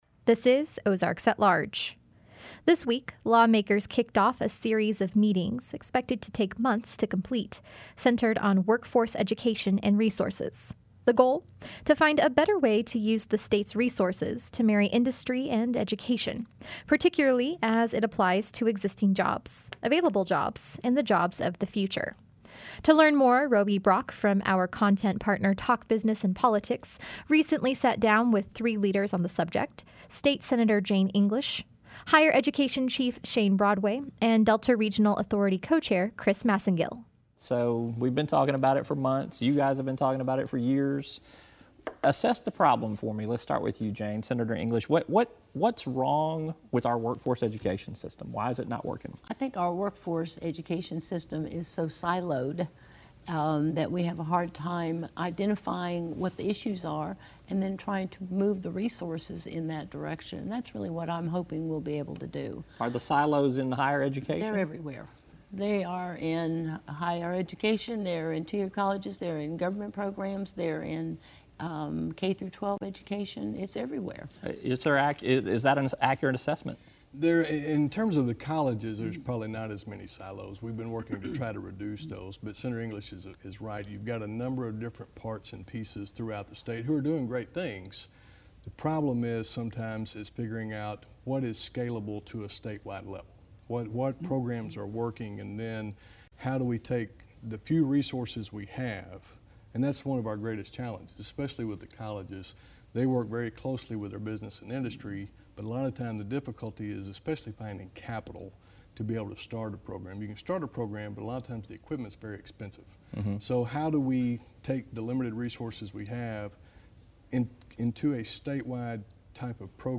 roundtable discussion